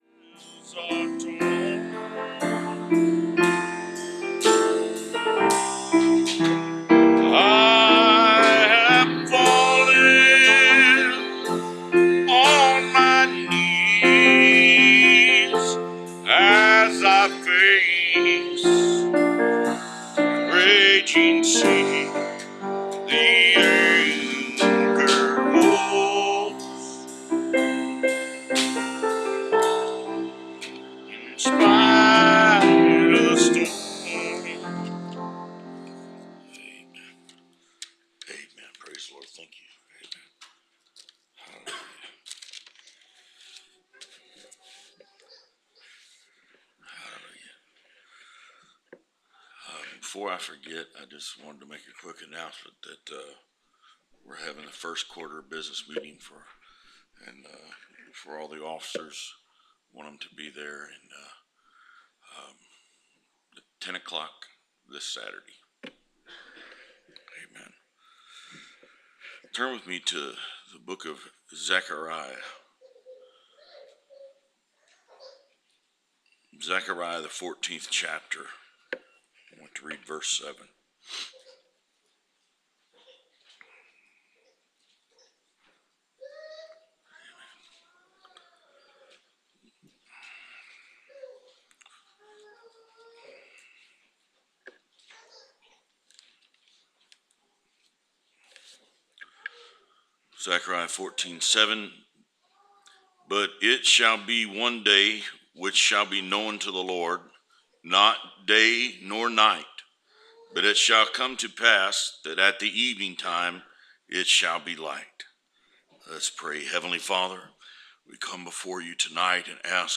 Preached Jan 29th, 2015